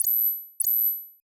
Knock Notification 5.wav